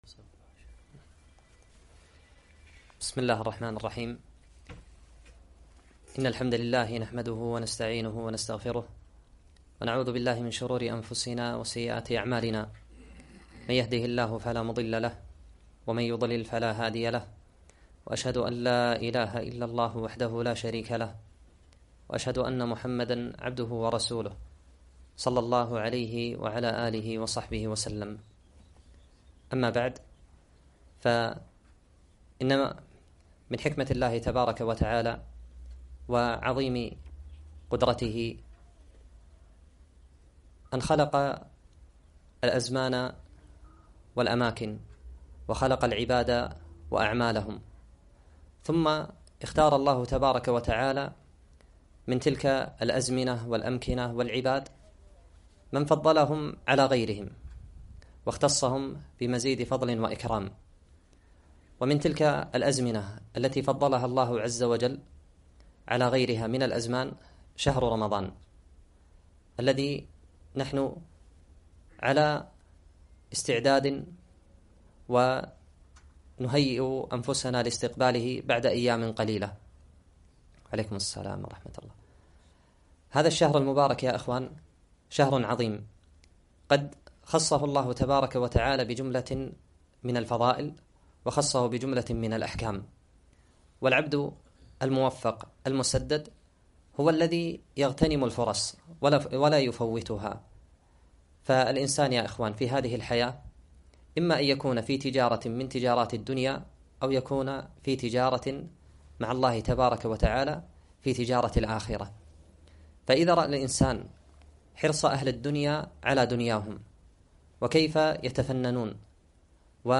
محاضرة - اغتنام شهر رمضان